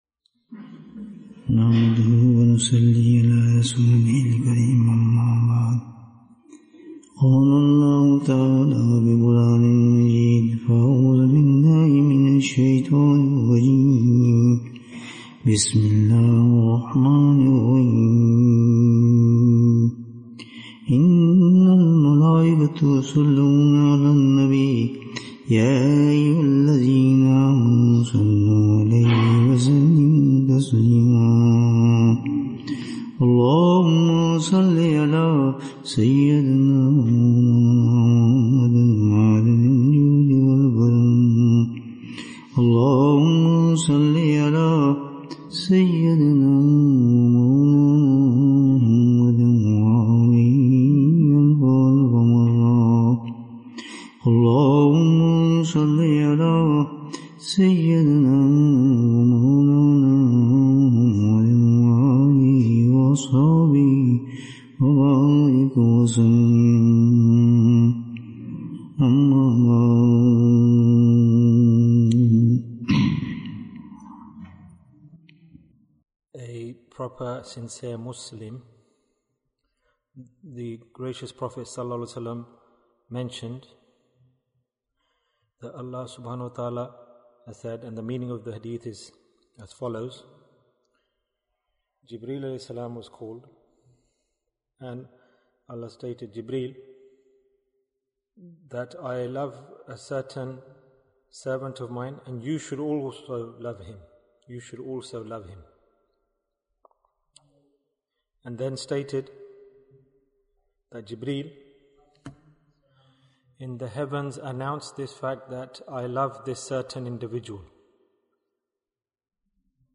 What do you Gain from the Love of Allah-Waalay? Bayan, 37 minutes22nd December, 2022